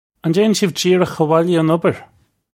Pronunciation for how to say
Un jay-un shiv jeer-ukh awolya oh-n ubber? (U)
This is an approximate phonetic pronunciation of the phrase.